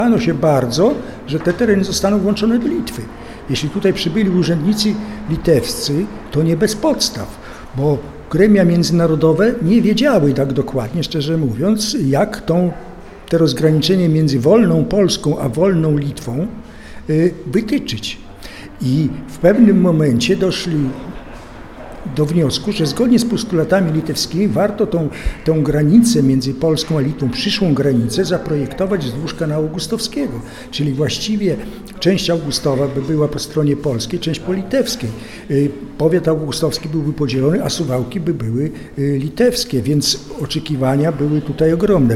Jak przypomina dyrektor, do tego momentu w Suwałkach trwała bowiem swoista trójwładza. W mieście funkcjonowała bowiem administracja Polski, Niemiec i Litwy, a przyszłość Suwałk była niepewna.